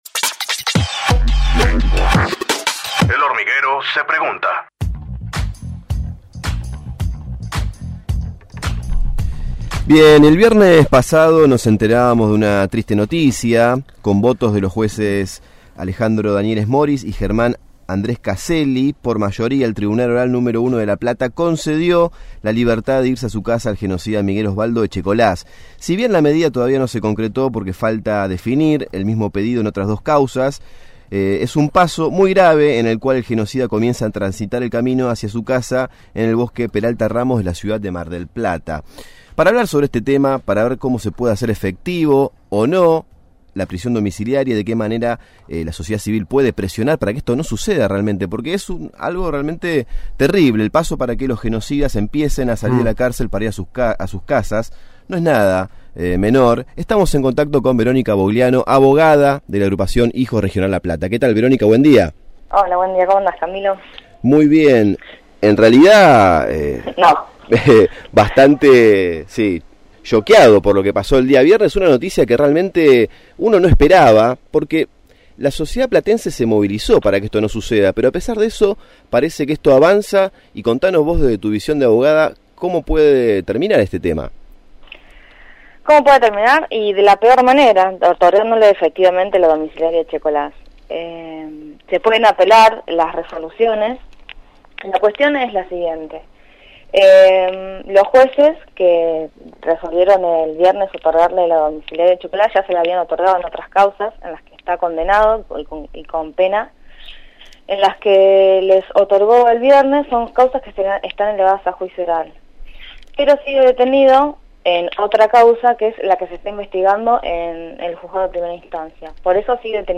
Conducción